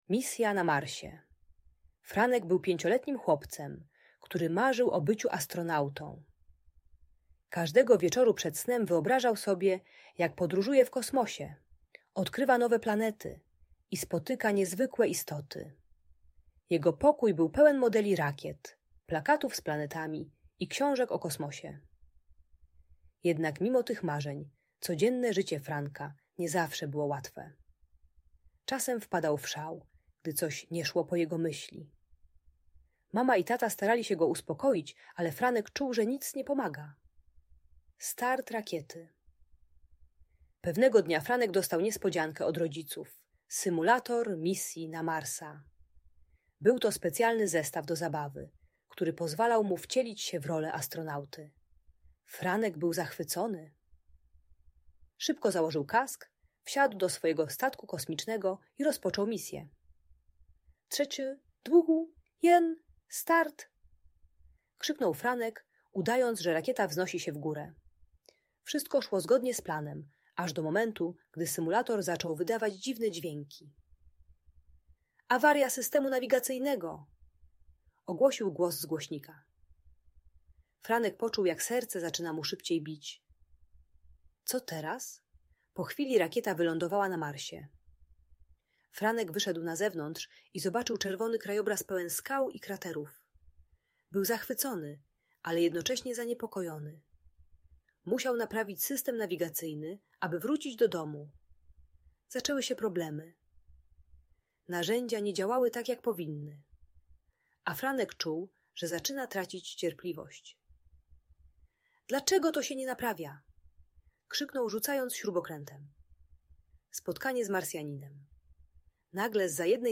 Misja na Marsie - Audiobajka